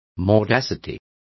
Complete with pronunciation of the translation of mordacity.